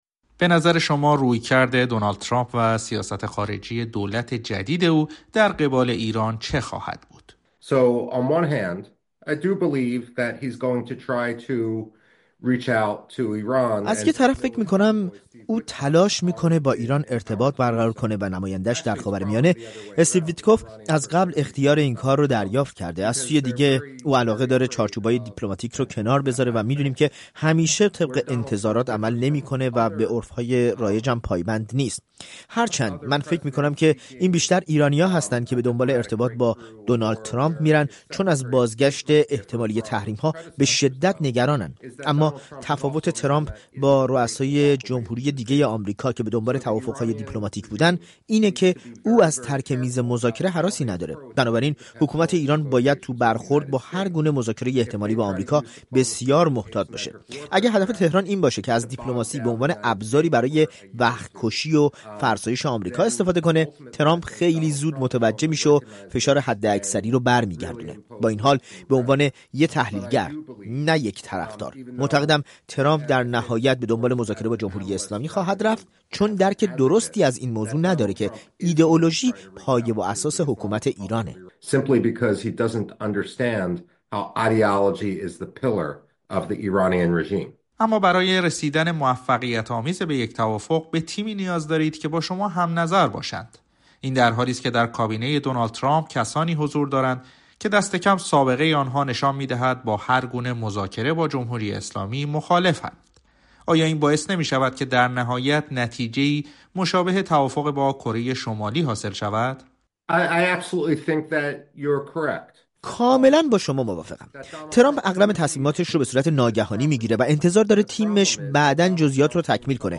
گفت‌وگو با مایکل روبین دربارۀ تقابل ترامپ با ایران و آیندۀ جمهوری اسلامی